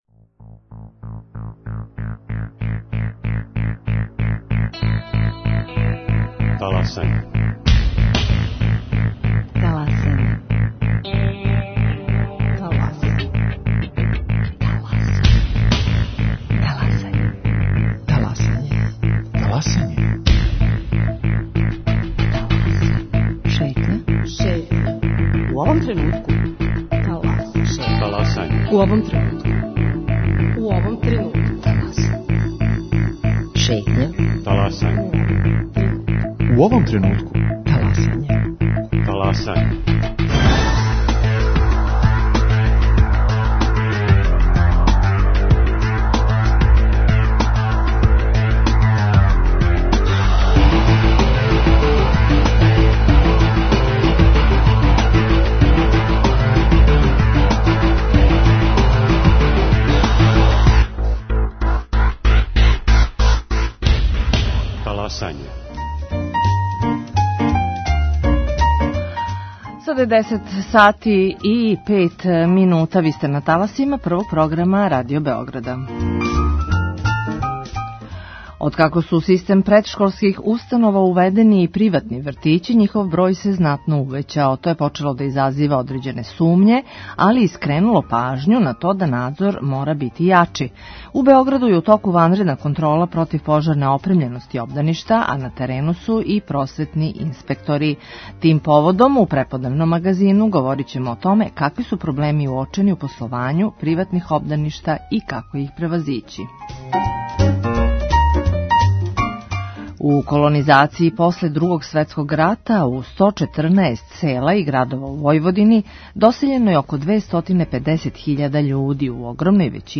У другом делу емисије чућемо како деци усадити свест о потреби заштите животне средине и рационалном коришћењу енергије. Наши саговорници биће представници неколико фирми укључених у пројекат Еко генијалци.